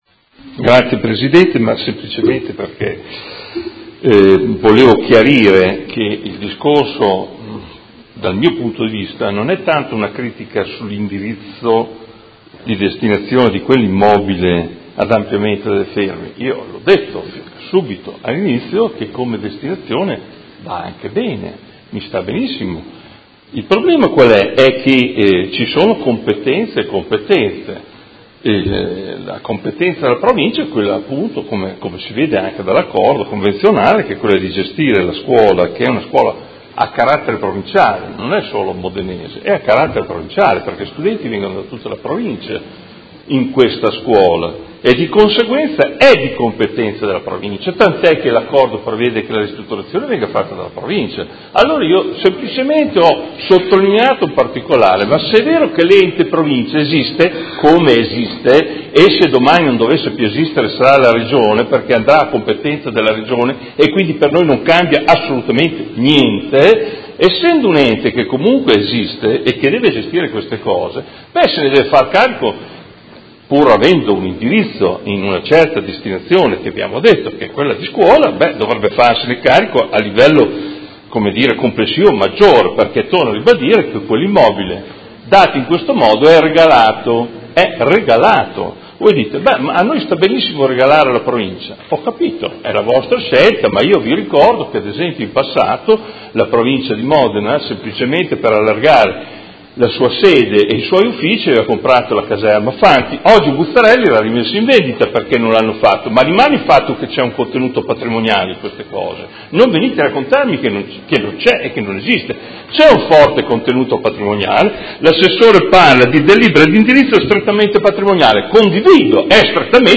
Seduta del 31/05/2018 Dichiarazione di voto.
Audio Consiglio Comunale